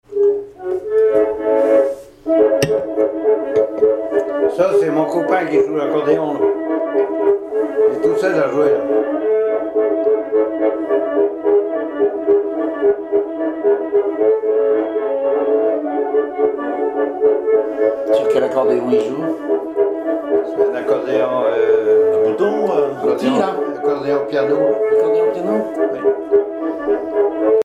danse : redowa
Pièce musicale inédite